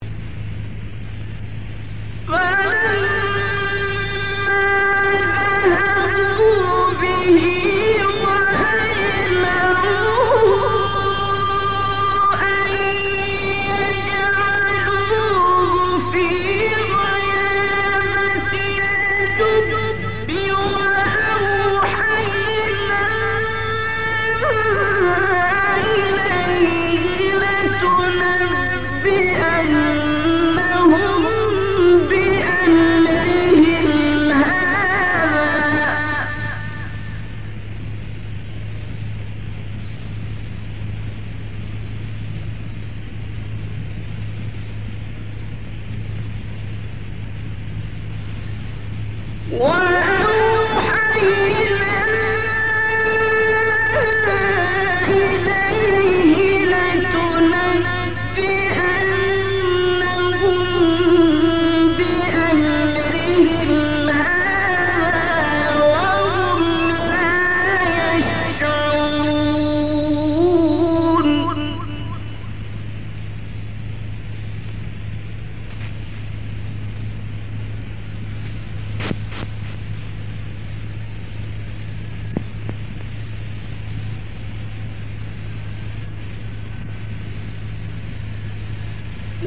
گروه شبکه اجتماعی: فرازهای صوتی از تلاوت قاریان برجسته مصری را می‌شنوید.
مقطعی از عبدالباسط محمد عبدالصمد/ سوره یوسف اجرا شده در دمشق در سال 1957میلادی